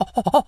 monkey_chatter_08.wav